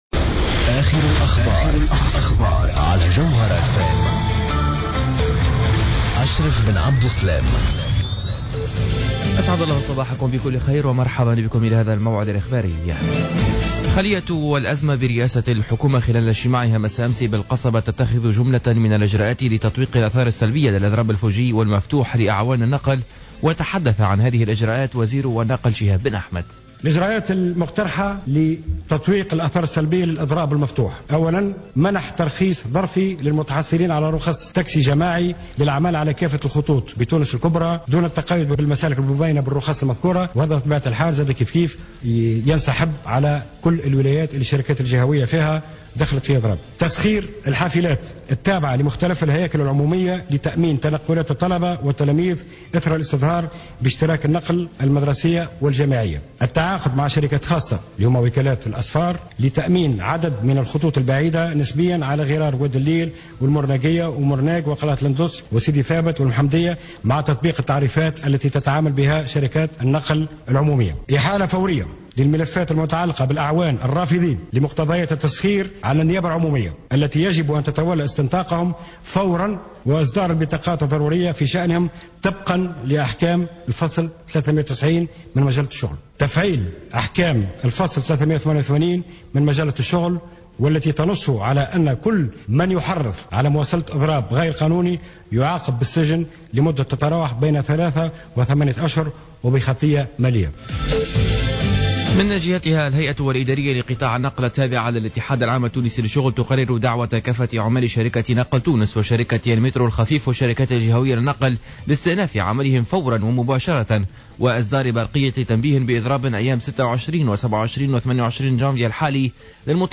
نشرة اخبار السابعة صباحا ليوم الجمعة 16-01-15